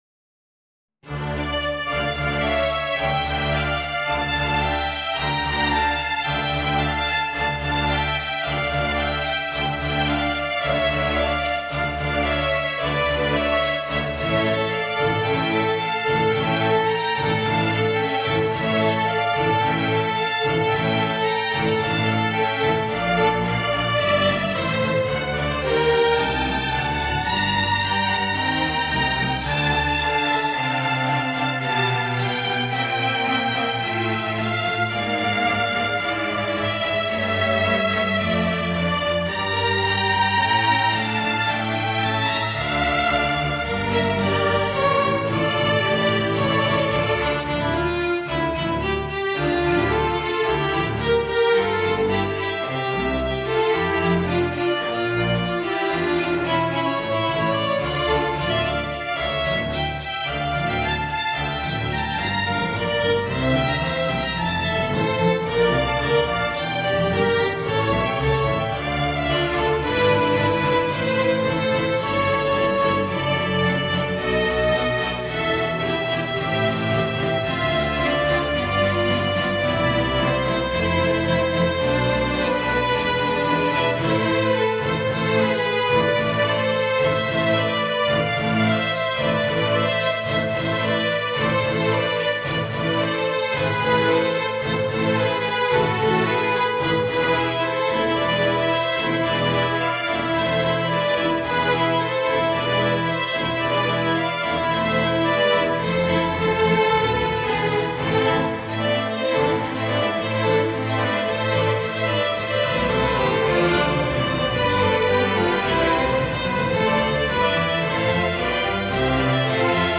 Violin concerto in d minor
I. Allegro II. Adagio III. Vivace This is concerto 43 written in the Italian school.